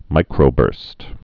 (mīkrō-bûrst)